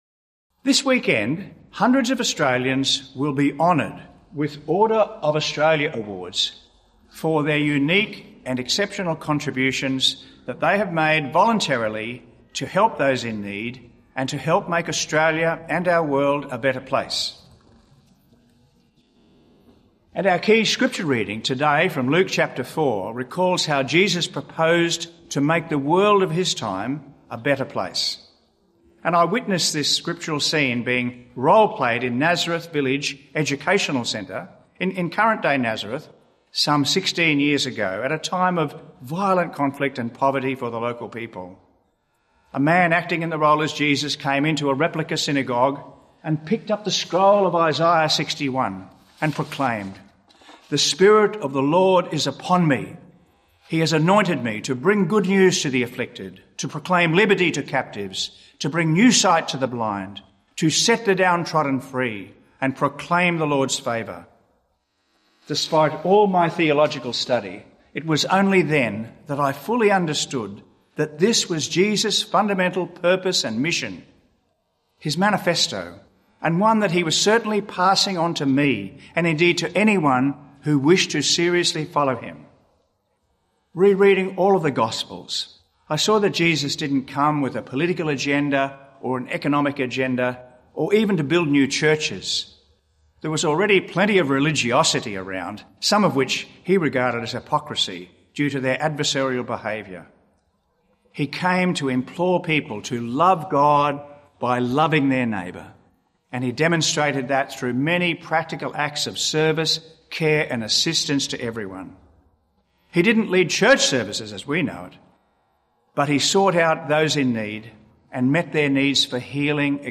Archdiocese of Brisbane Third Sunday in Ordinary Time - Two-Minute Homily